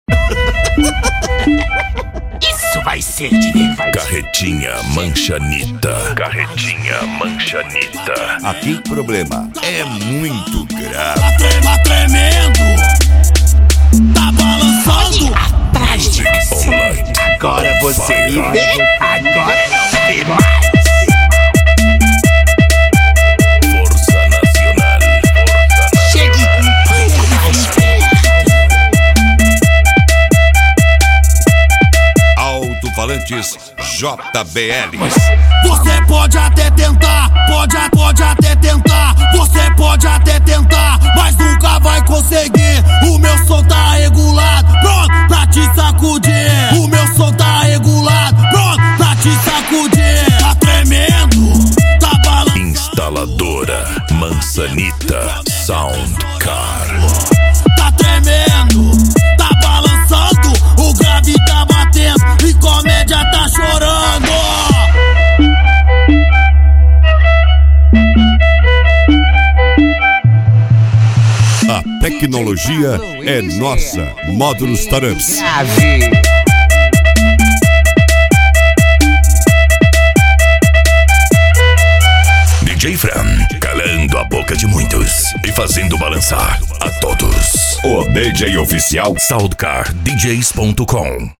Bass
Racha De Som